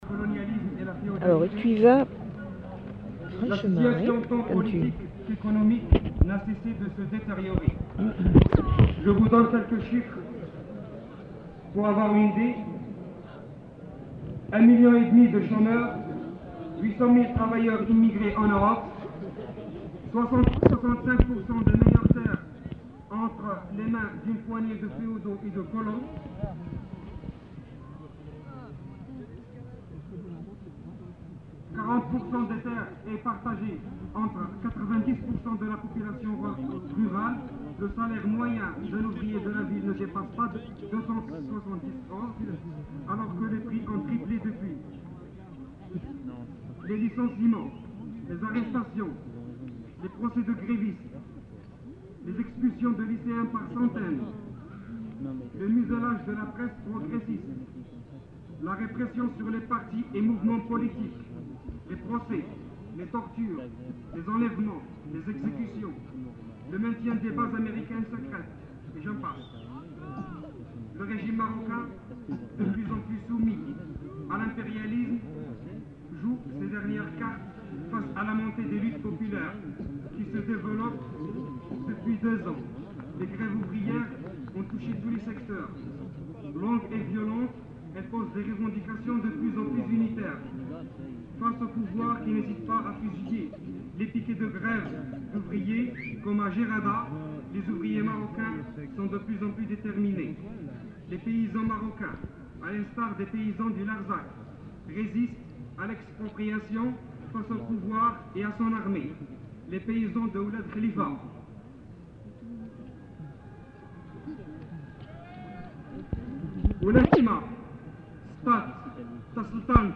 Lieu : [sans lieu] ; Aveyron
Genre : parole